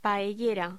Locución: Paellera